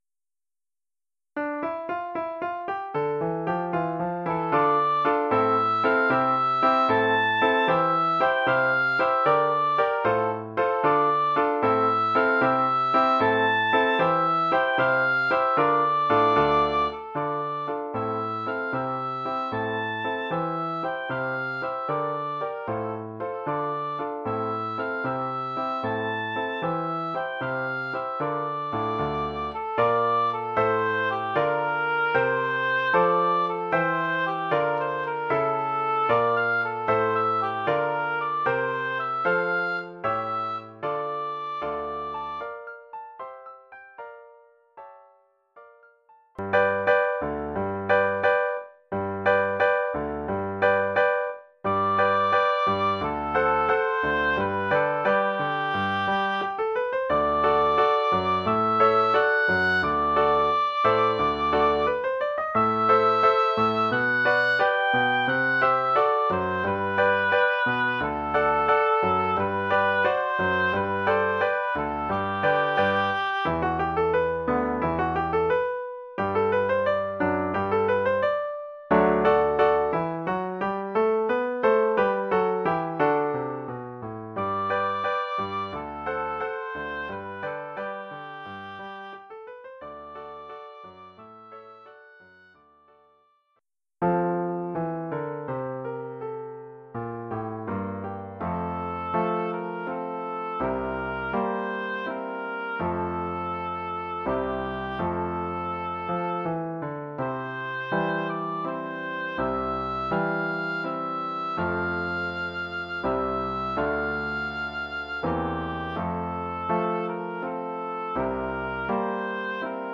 Formule instrumentale : Hautbois et piano
Oeuvre en cinq mouvements
pour hautbois et piano.